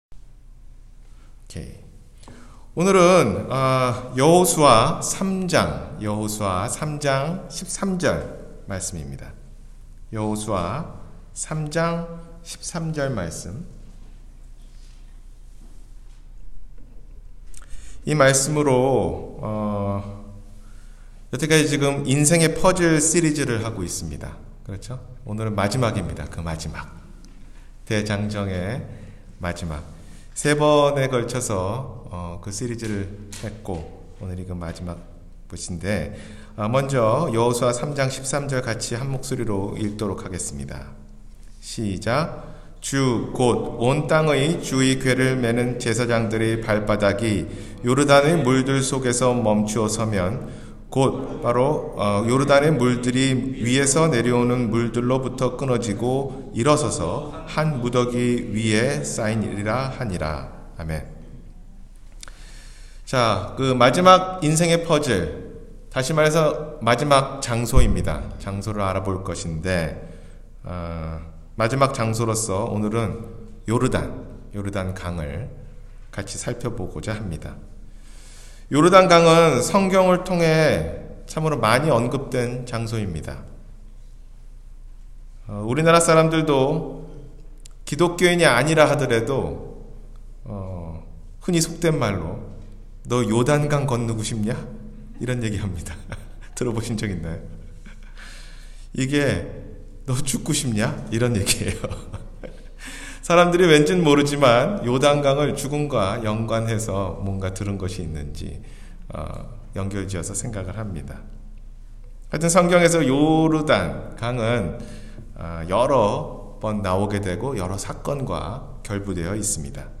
인생의 퍼즐 4: 요르단 – 주일설교